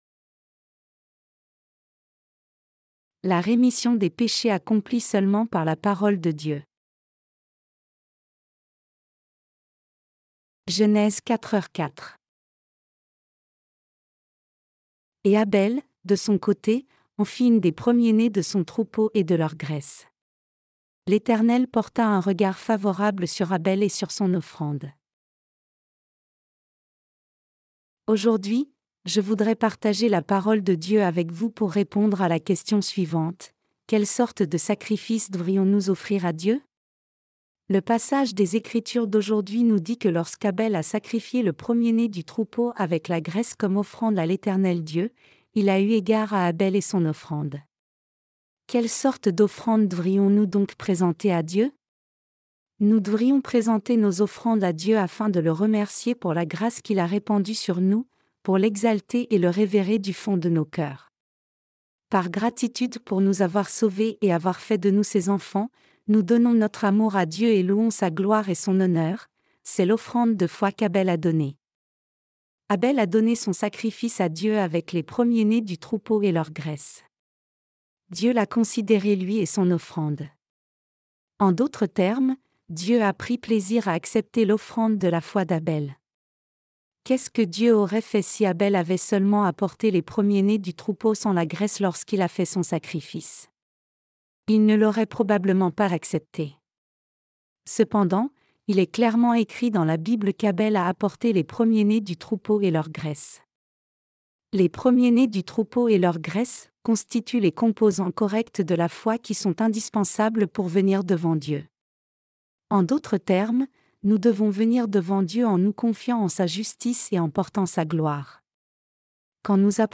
Sermons sur la Genèse (V) - LA DIFFERENCE ENTRE LA FOI D’ABEL ET LA FOI DE CAÏN 4.